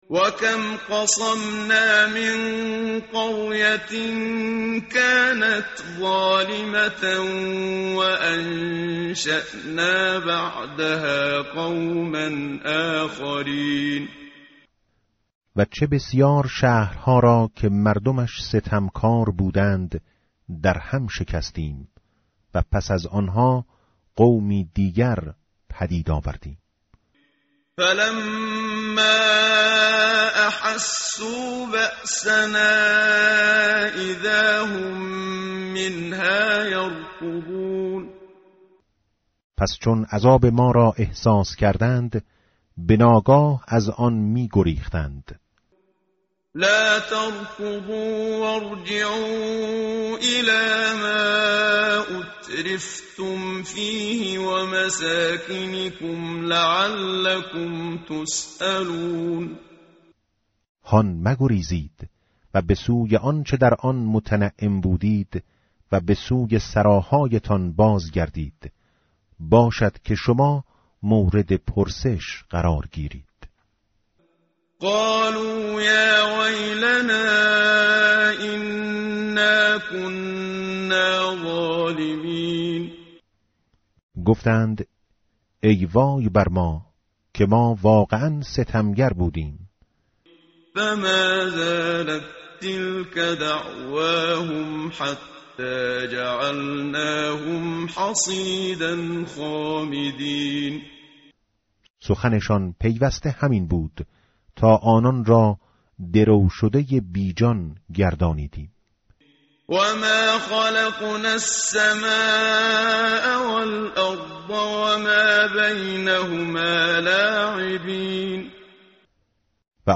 متن قرآن همراه باتلاوت قرآن و ترجمه
tartil_menshavi va tarjome_Page_323.mp3